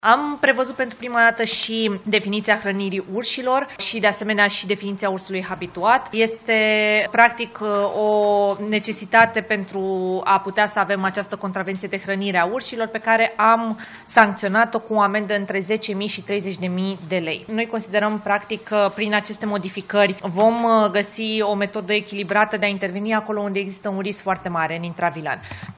Ministra Mediului, Diana Buzoianu a precizat, la finalul ședinței de guvern, că în afara localităților, se menține aplicarea graduală a măsurilor de intervenție în cazul urșilor.